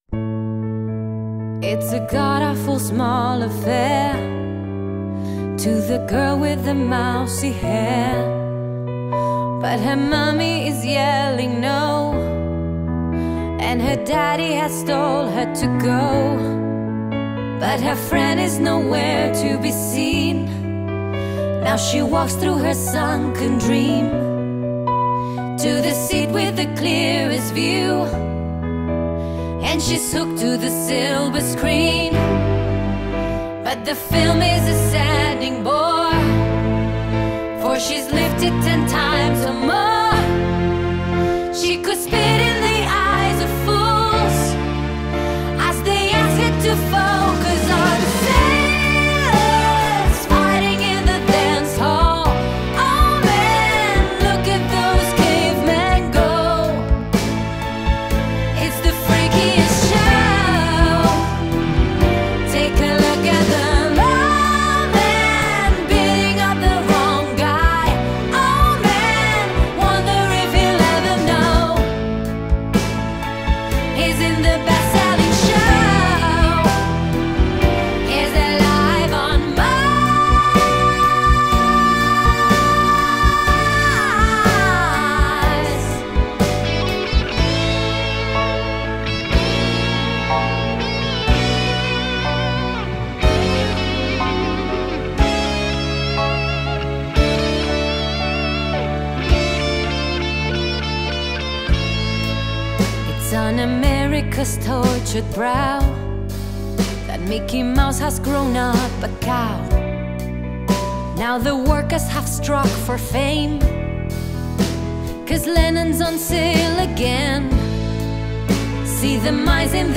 Soundtrack, Rock, Jazz